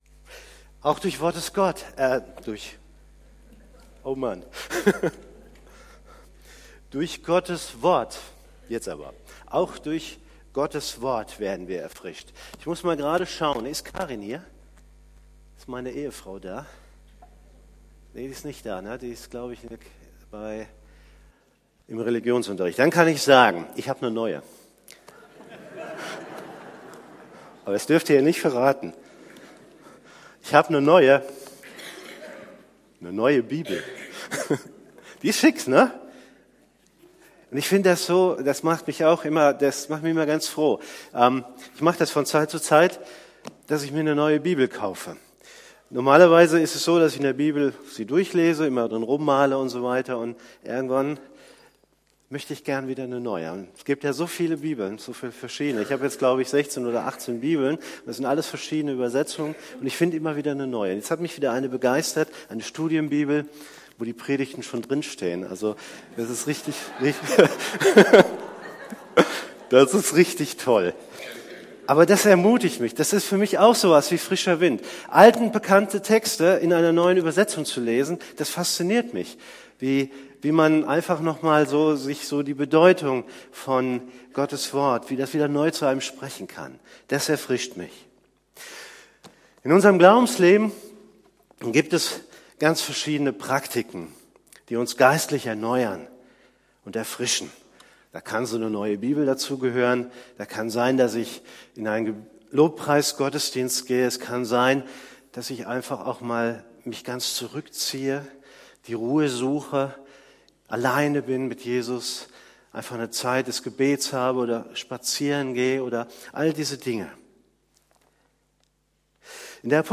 predigt.mp3